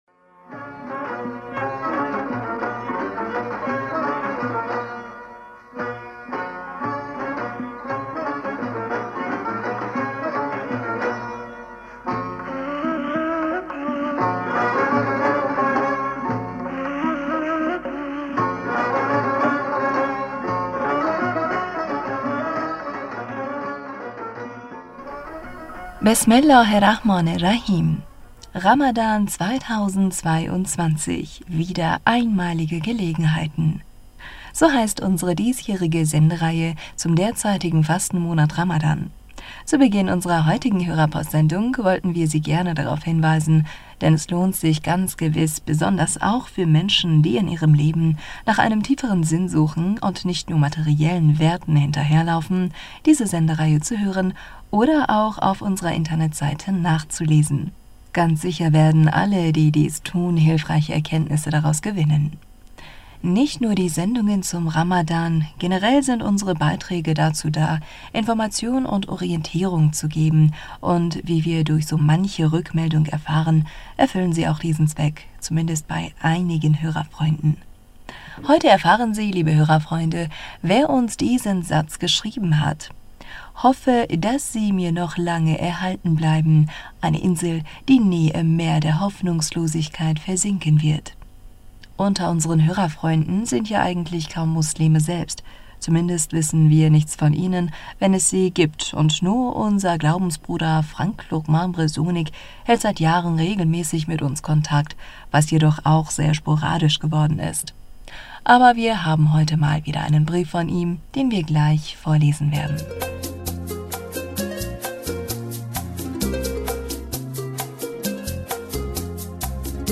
Hörerpostsendung am 10.April 2022 Bismillaher rahmaner rahim - Ramadan 2022 -- wieder einmalige Gelegenheiten!!